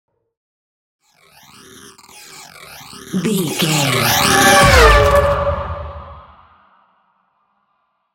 Sci fi vehicle whoosh large
Sound Effects
Atonal
dark
futuristic
whoosh